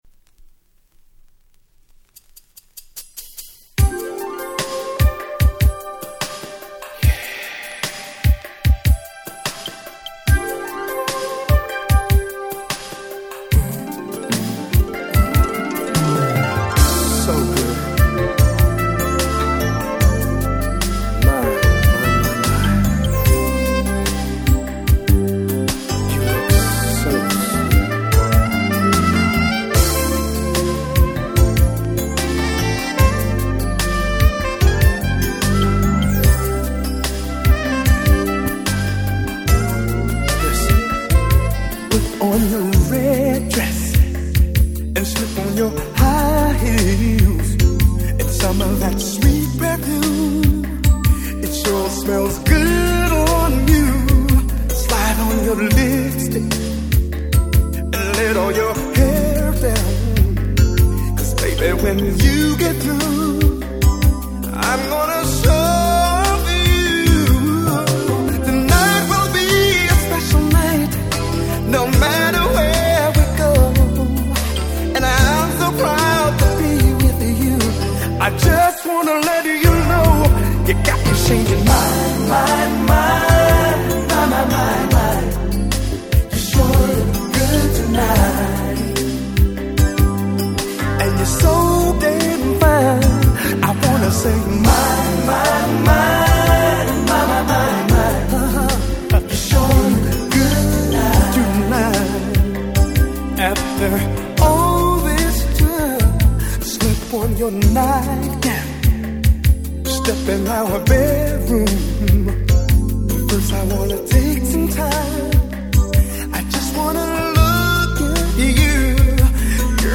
90' Super Hit R&B LP !!